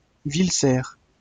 Villecerf (French pronunciation: [vilsɛʁ(f)]